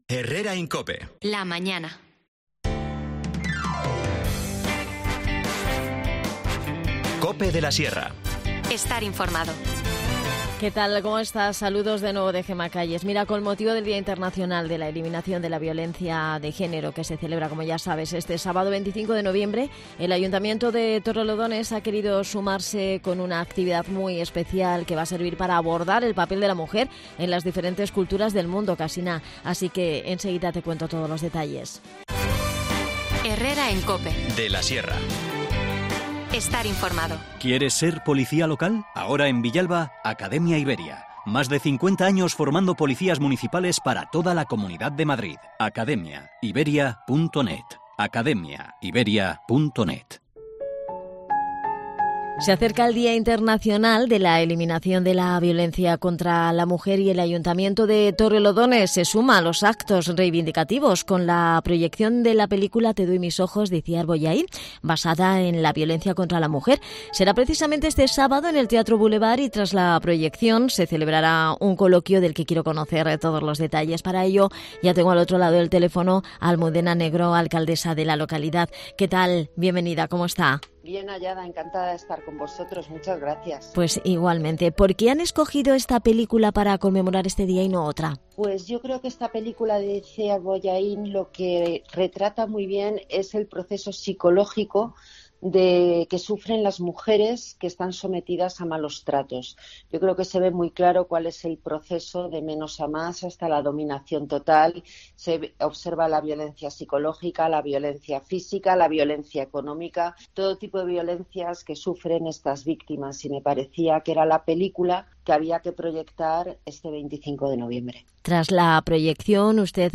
Charlamos en el programa con Almudena Negro, alcaldesa de Torrelodones.